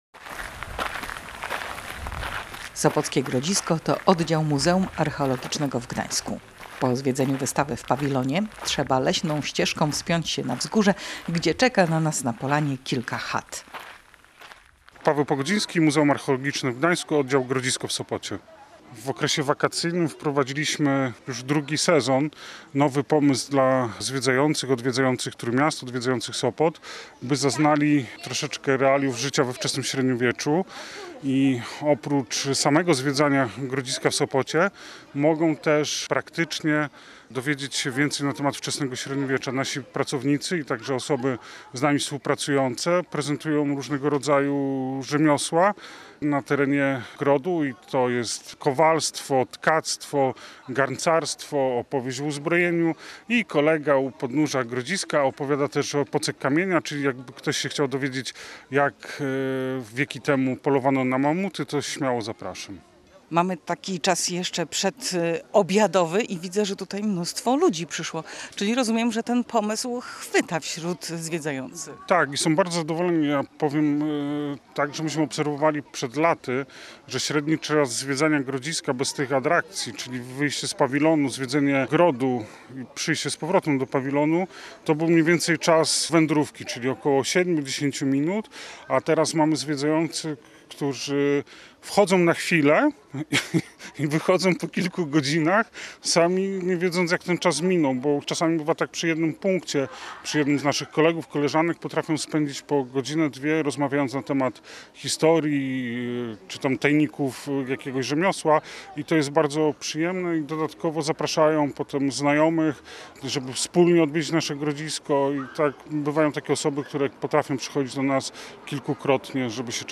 Pracownicy w strojach z epoki opowiadają o słowiańskim grodzie, czyli osadzie obronnej która istniała tu dużo wcześniej zanim powstało miasto.